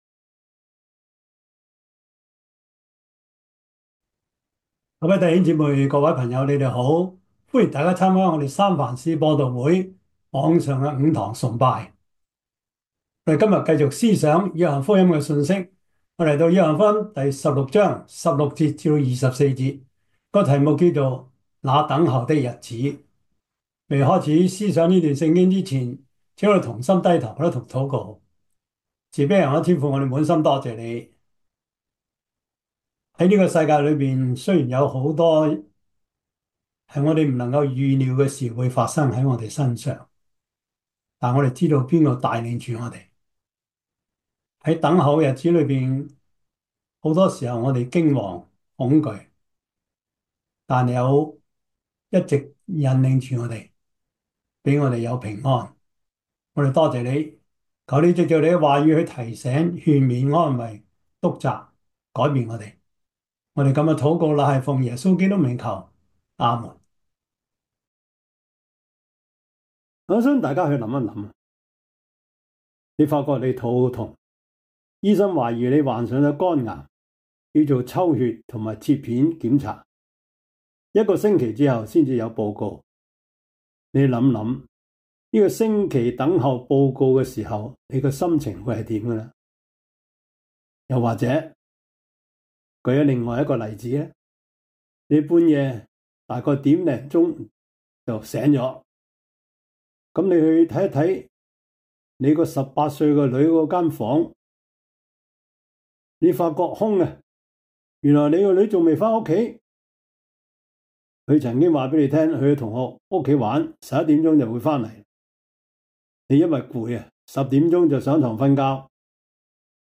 約翰福音 16:16-24 Service Type: 主日崇拜 約翰福音 16:16-24 Chinese Union Version
Topics: 主日證道 « 不信的理由 第八十三課: 天國與政治 – 第二十講 天國是什麼？